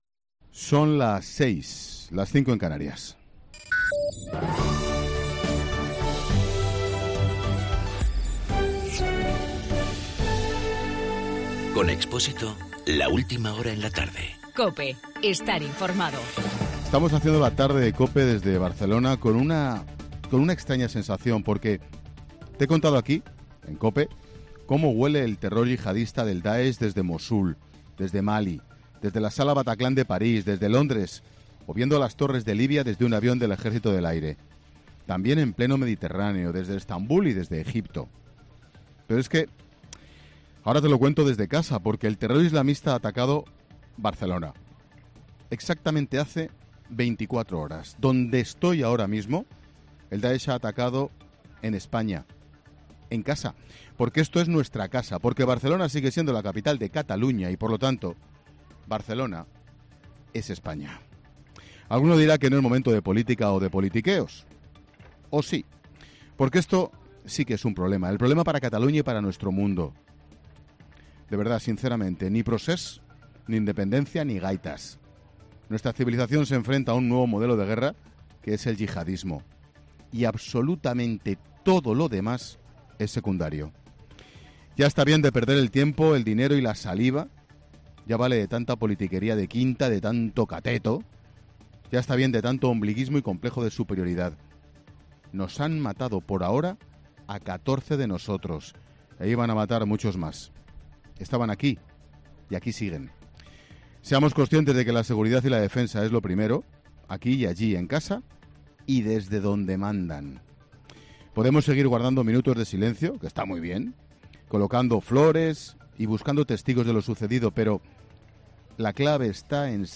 AUDIO: 'La Tarde' desde Barcelona después del atentado que ha dejado 14 muertos y 130 heridos.
Monólogo de Expósito